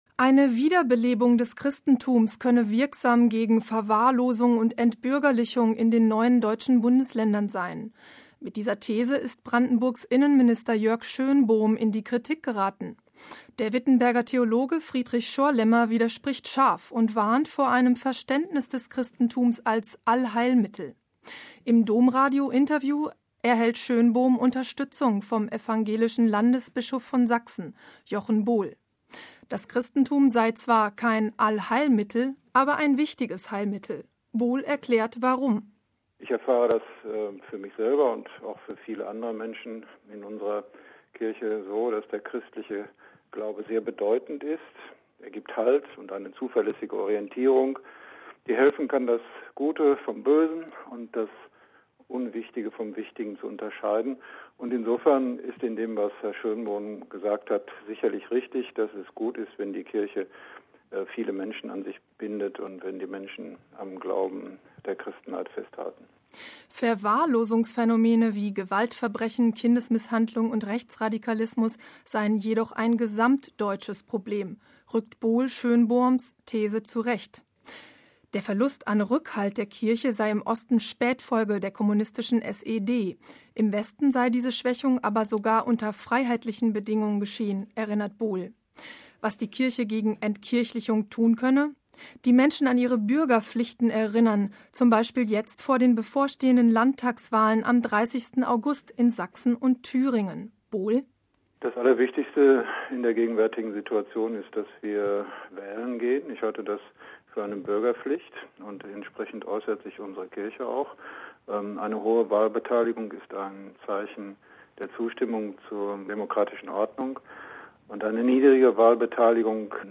Im Domradio-Interview erhält Schönbohm Unterstützung vom evangelischen Landesbischof von Sachsen, Jochen Bohl. Das Christentum sei zwar kein „Allheilmittel, aber ein wichtiges Heilmittel“.